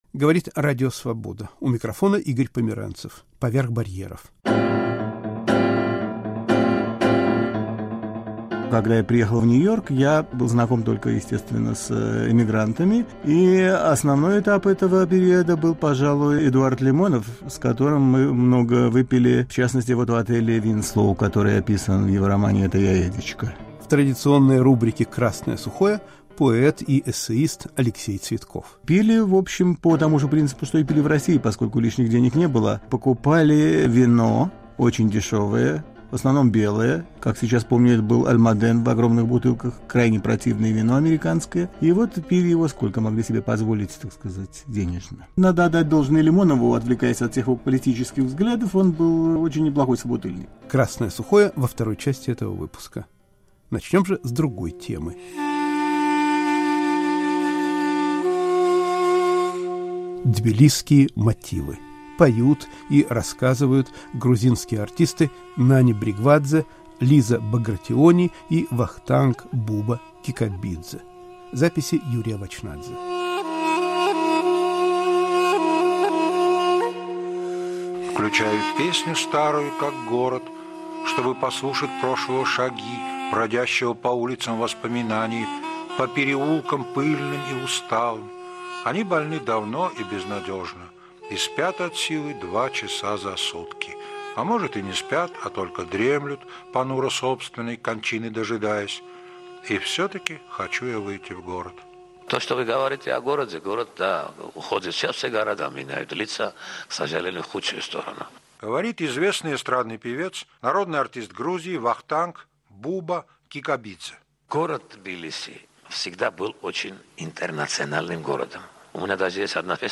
О своей жизни рассказывают деревенские жители *** «Красное сухое».